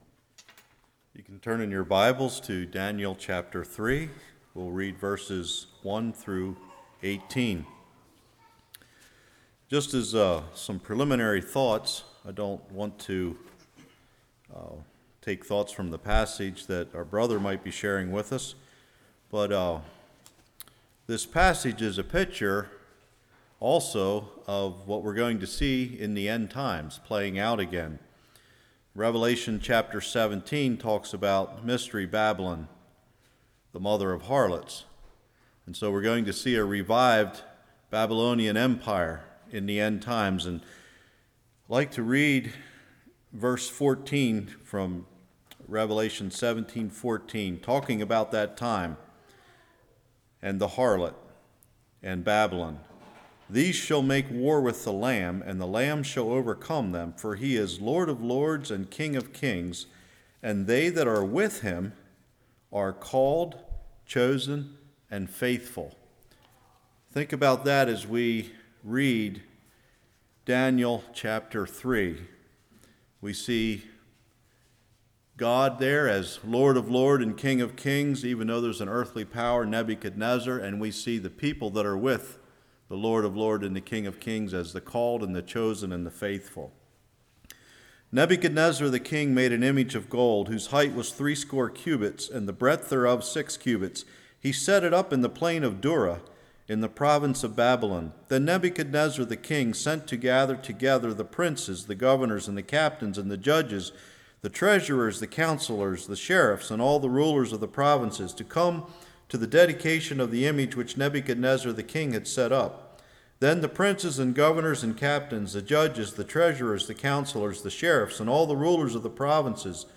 Daniel 3:1-18 Service Type: Evening Nebuchadnezzar’s image Jews respond God’s response « Where Grace & Truth Meet Sow in Tears